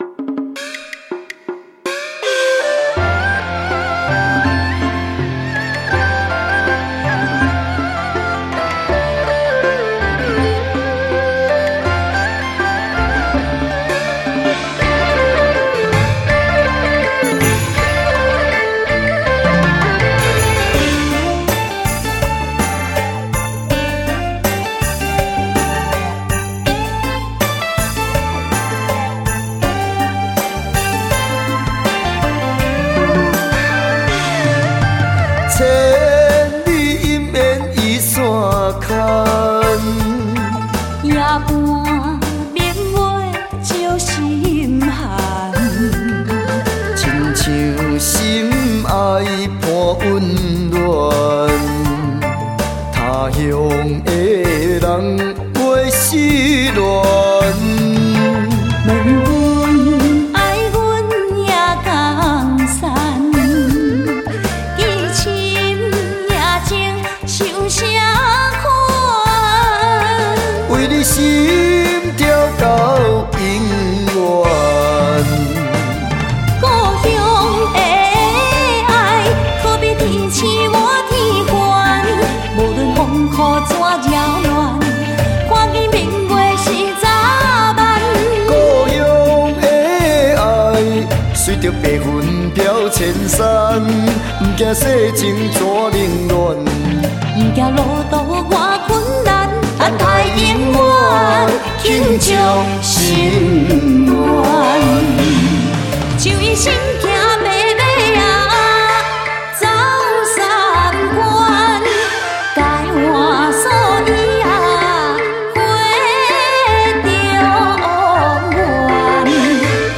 那卡西天后